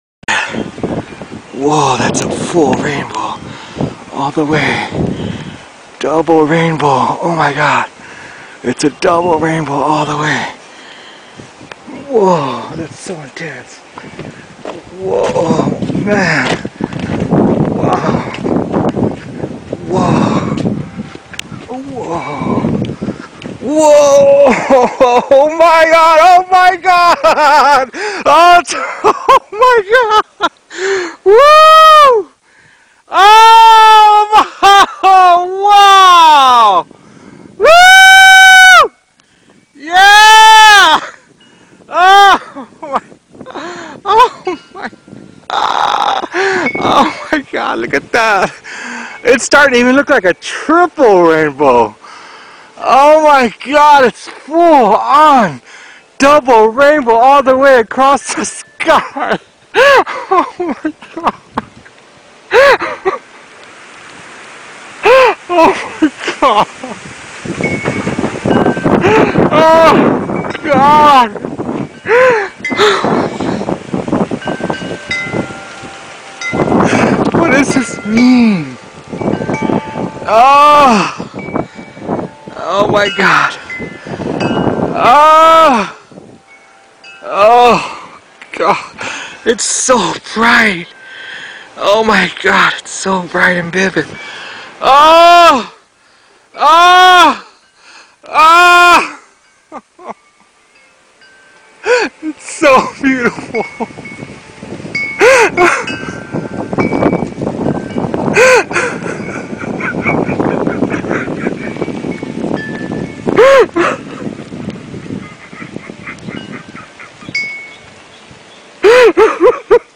I extracted Yosemite Bear's audio the quick and dirty way, using Wiretap Studio, and now I can't wait for monday morning to come around so I can enjoy A FULL DOUBLE RAINBOW bright and early in the morning to start off the day.